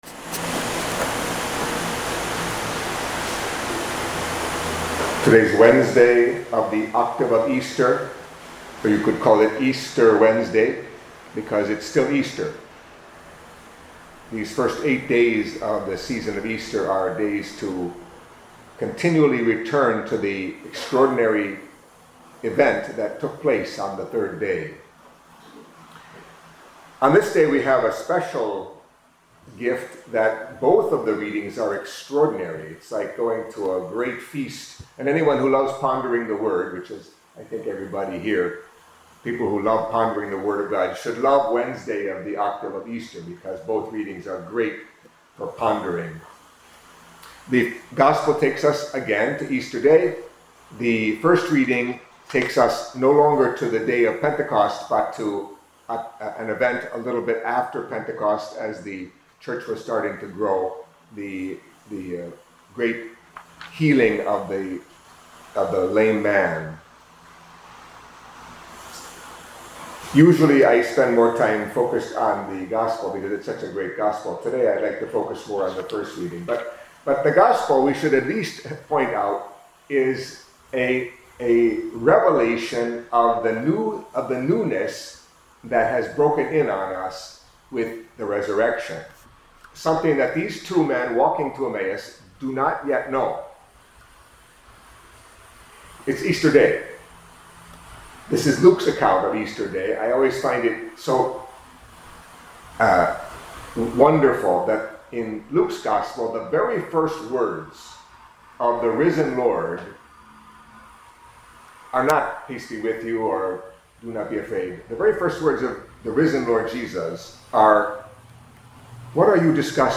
Catholic Mass homily for Wednesday in the Octave of Easter